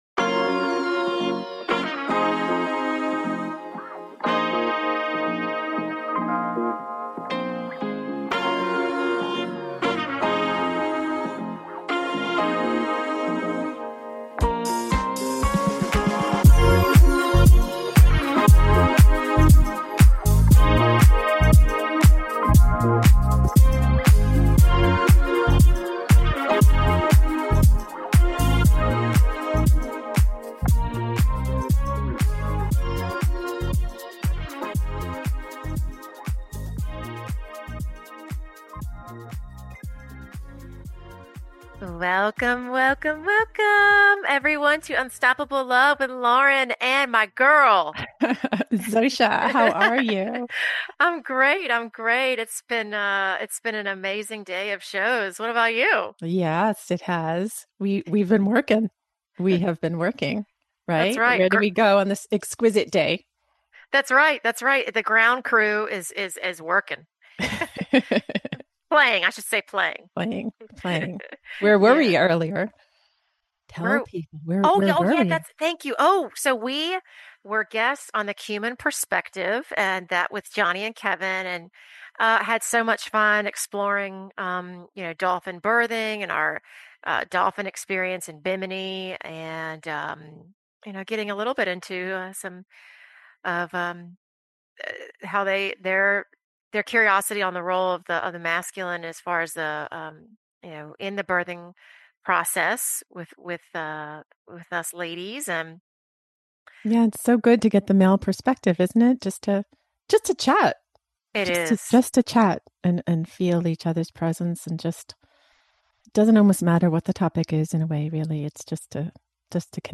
Her preferred communication style is spontaneous and organic, while creating an environment of authenticity and peace, so sharing from their hearts is easy and natural for her listeners.